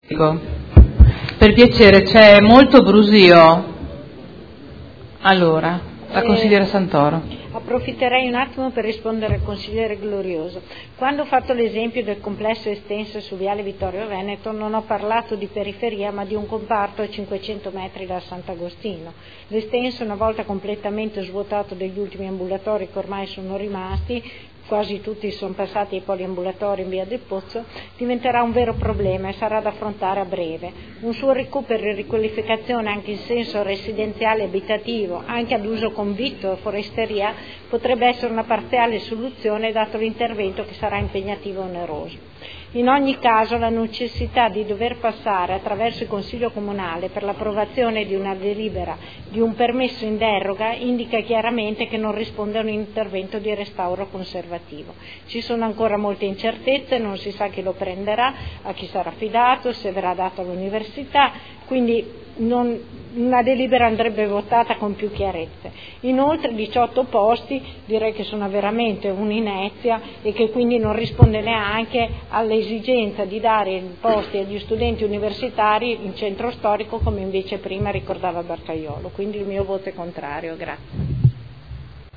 Seduta del 9 gennaio. Proposta di deliberazione: Nulla osta al rilascio del permesso di costruire in deroga agli strumenti urbanistici presentato da Fondazione Cassa di Risparmio di Modena per realizzare un convitto all’interno del nuovo polo culturale nel complesso immobiliare Sant’Agostino (Ex Ospedale Civile). Dichiarazioni di voto